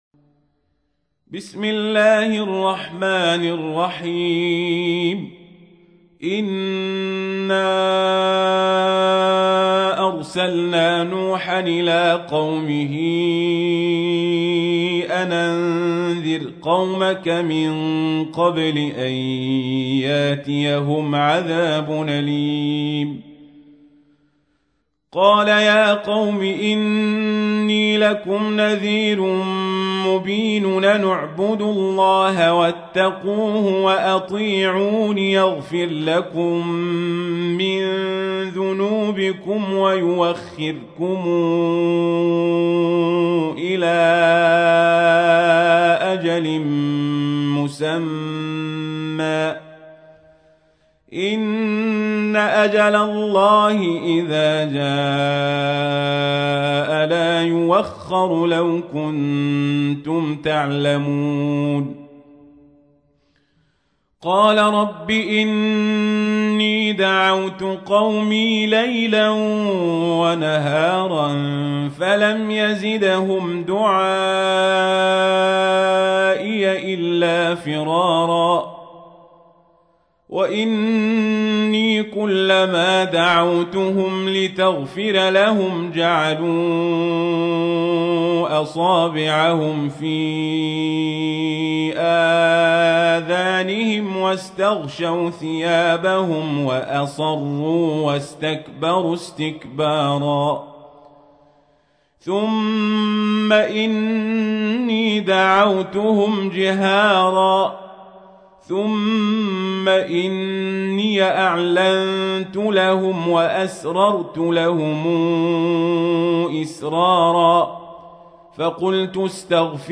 تحميل : 71. سورة نوح / القارئ القزابري / القرآن الكريم / موقع يا حسين